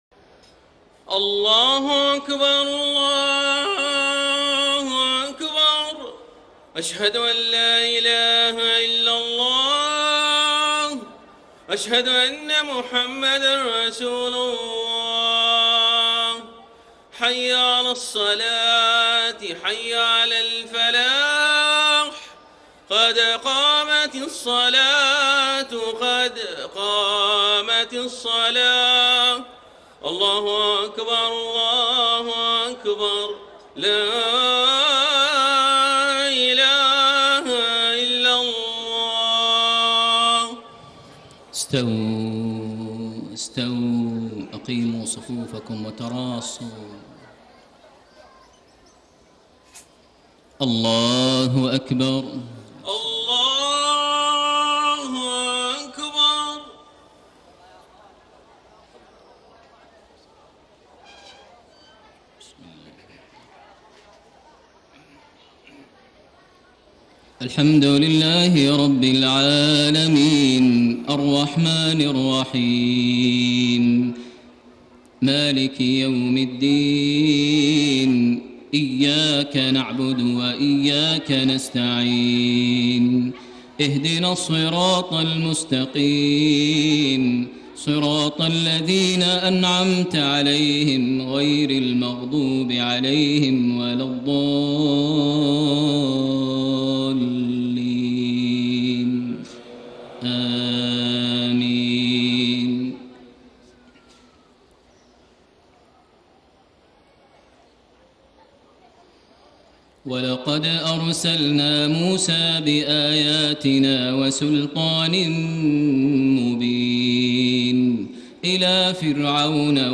صلاة العشاء 3-2-1435 من سورة هود > 1435 🕋 > الفروض - تلاوات الحرمين